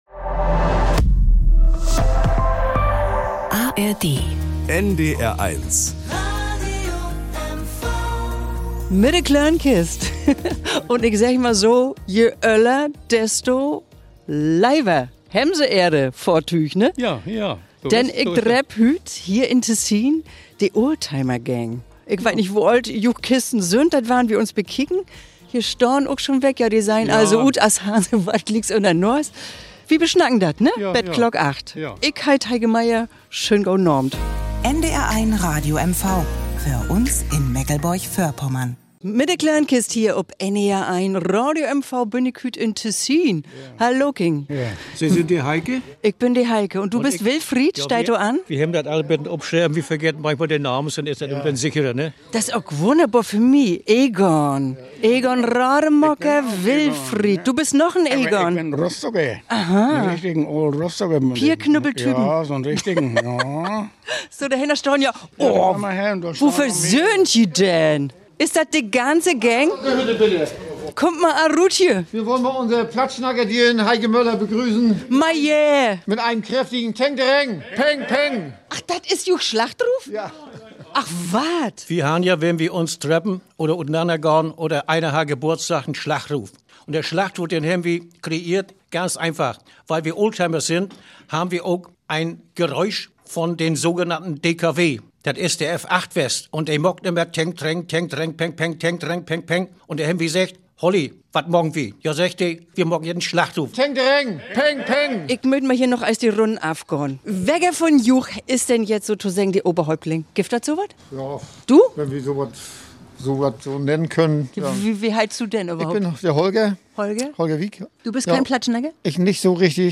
Beschreibung vor 4 Tagen Ihr Schlachtruf ist dem Knattern des DKW F8 nachempfunden. Tengtereng Peng Peng heißt es, wenn einer Geburtstag hat, wenn eine Ausfahrt ansteht, oder sich die Männer einfach in ihrer Garage treffen.